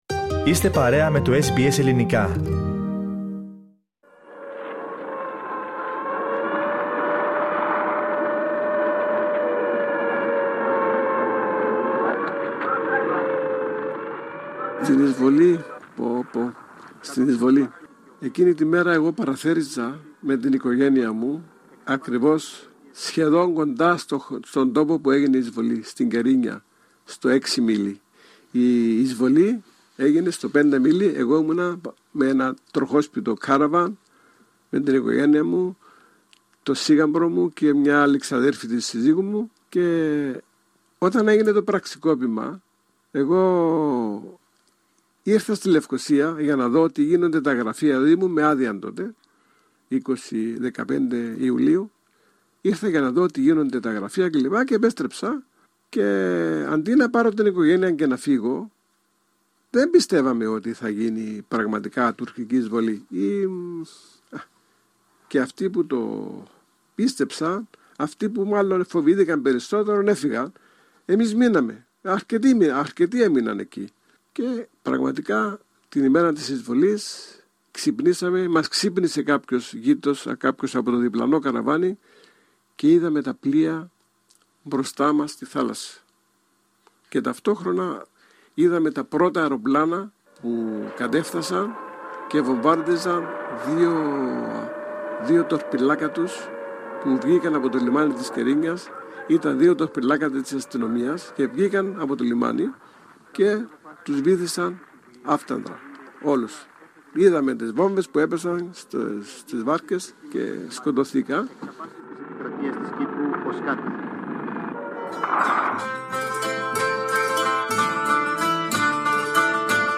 Αναμεταδίδουμε την συνέντευξη που μας παραχώρησε με αφορμή την 48η επέτειο της εισβολής του Αττίλα που οδήγησε στην διχοτόμηση της Κύπρου.